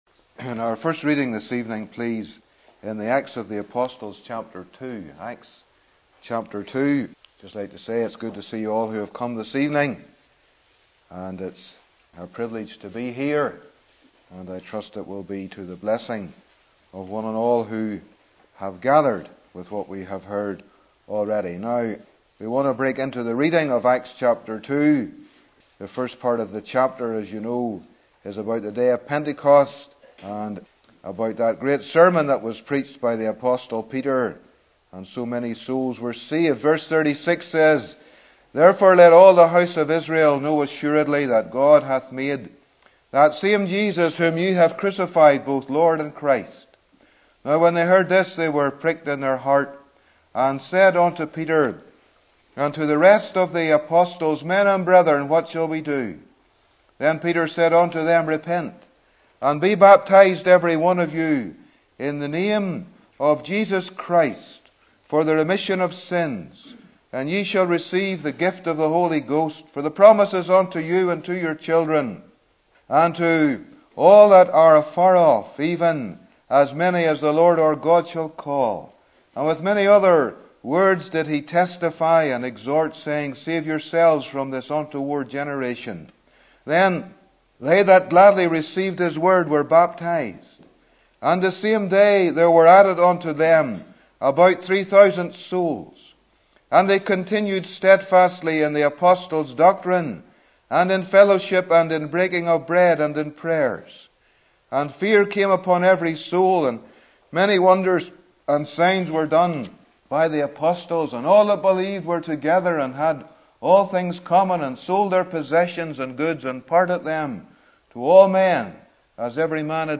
Easter Conference 2011